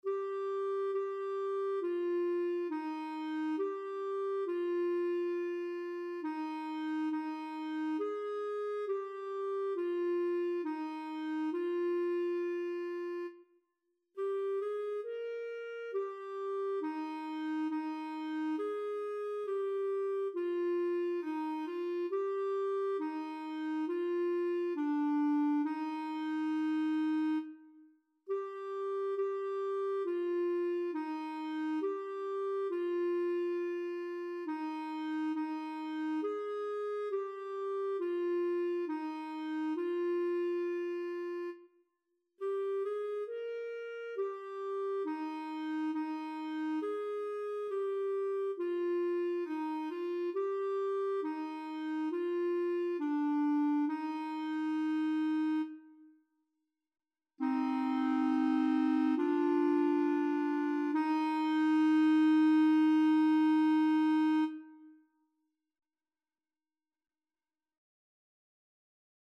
Title: Espírito divino, acende em mim Composer: Anonymous (Traditional) Lyricist: Number of voices: 1v Voicing: Unison Genre: Sacred, Hymn
Language: Portuguese Instruments: Organ